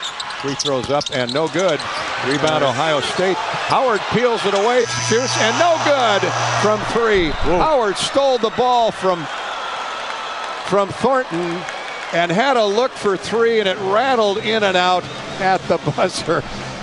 bigcall1.mp3